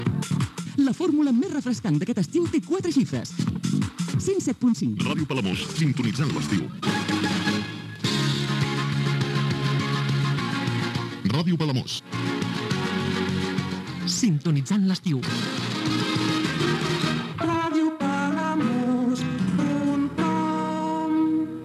Indicatiu d'estiu de l'emissora